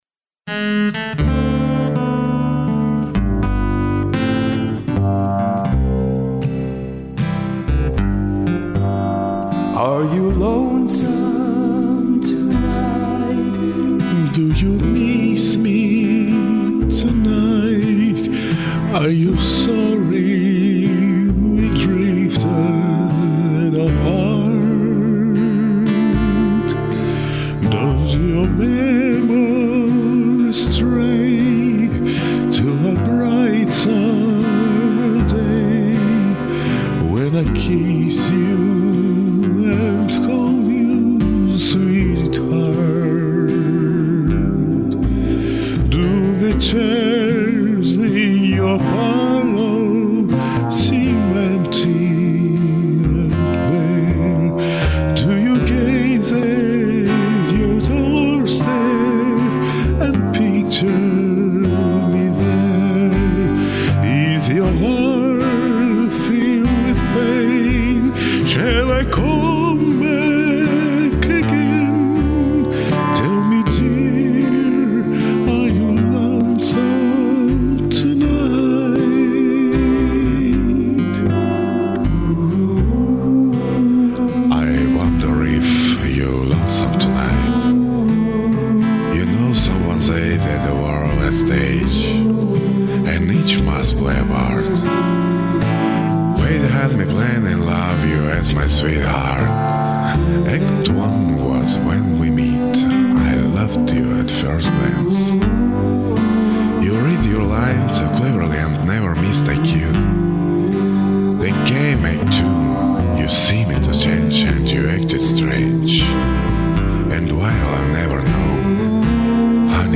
Бархатный баритон, а в речитативе - мёд и патока!